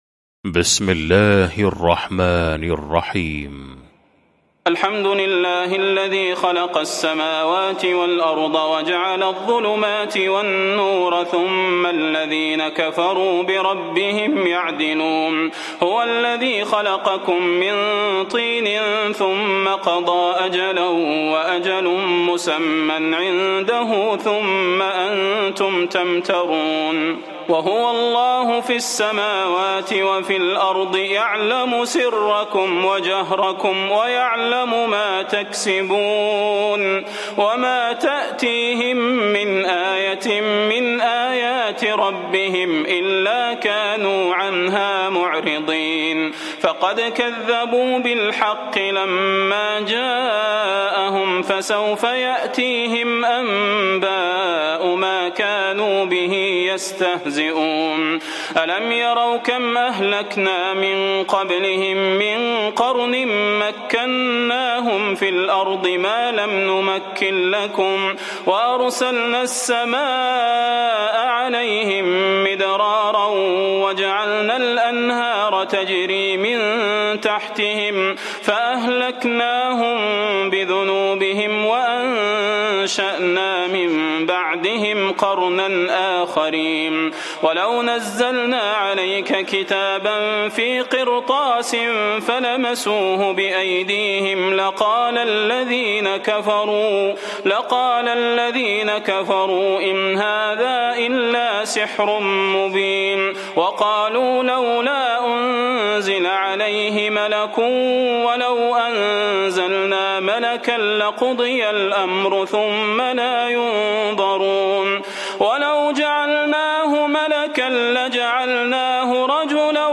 المكان: المسجد النبوي الشيخ: فضيلة الشيخ د. صلاح بن محمد البدير فضيلة الشيخ د. صلاح بن محمد البدير الأنعام